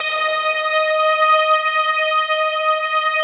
SI1 PLUCK08L.wav